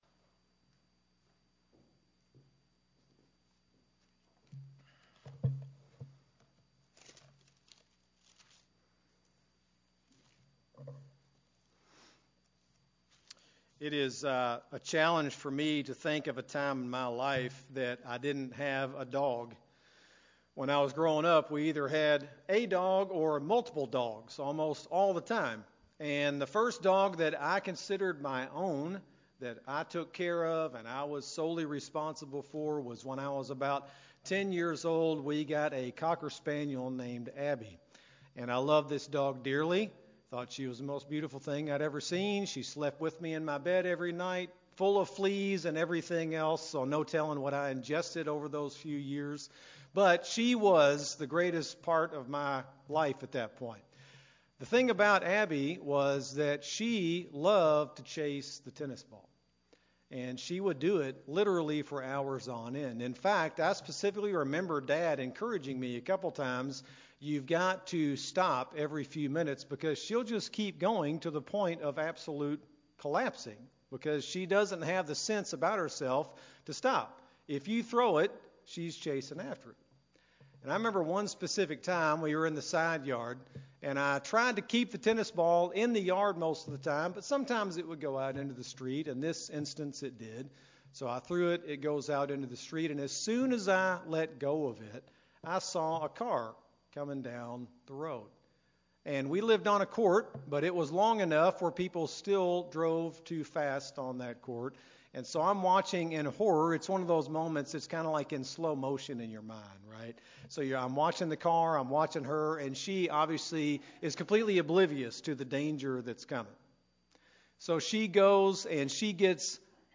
Sermon-8-21-22-CD.mp3